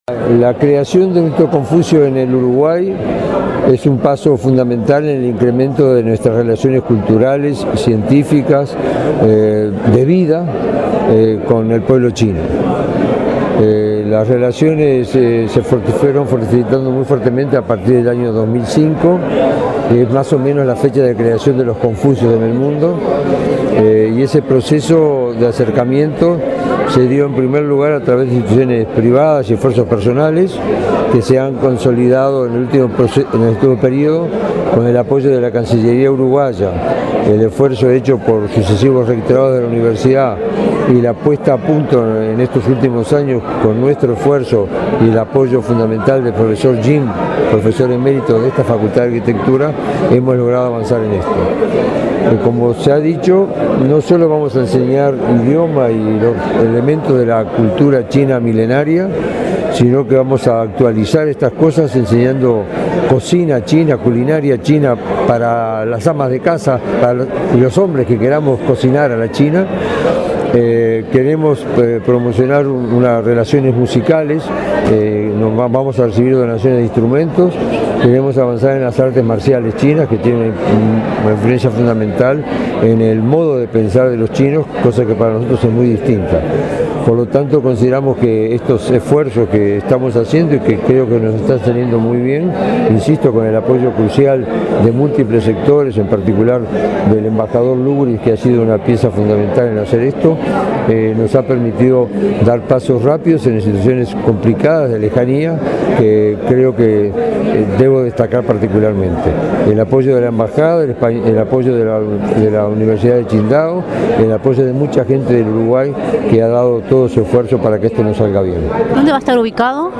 La creación de un Instituto Confucio en Uruguay es un paso fundamental en el incremento de las relaciones culturales y científicas con el pueblo chino, sostuvo el rector de la Udelar, Roberto Markarián, en la inauguración del instituto en Casa Lago de la Facultad de Humanidades. Se enseñará desde marzo la lengua y la cultura chinas, cocina, música y artes marciales.